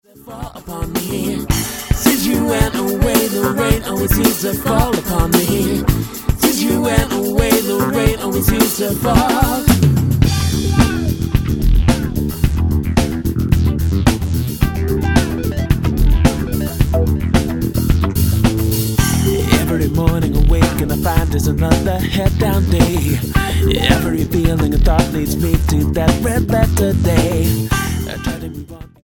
funk band
R&B
Style: Jazz